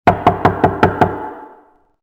Knock.wav